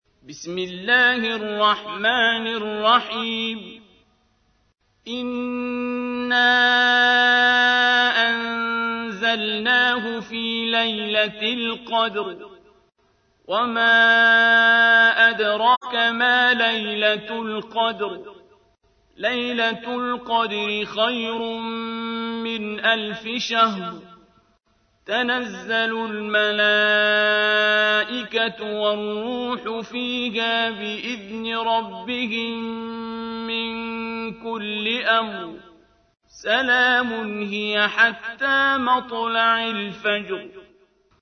تحميل : 97. سورة القدر / القارئ عبد الباسط عبد الصمد / القرآن الكريم / موقع يا حسين